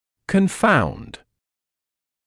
[kən’faund][кэн’фаунд]ставить в тупик, приводить в замешательство